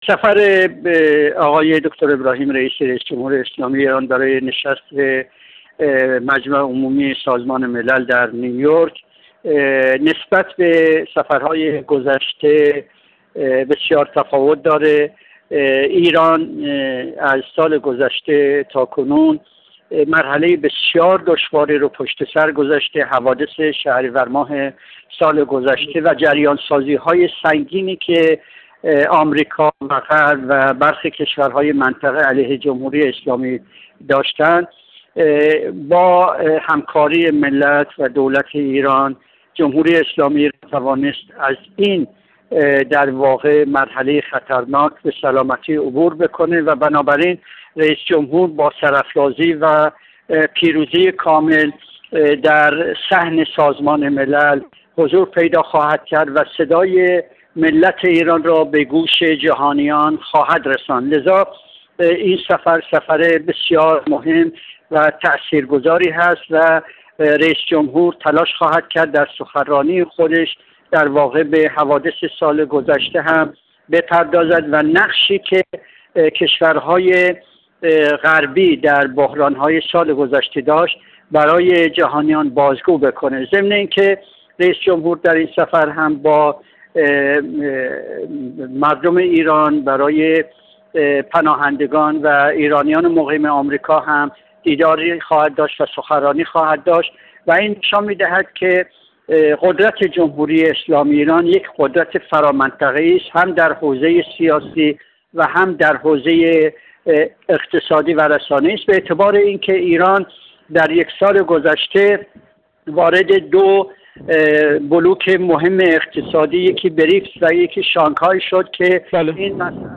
کارشناس مسائل منطقه
گفت‌وگو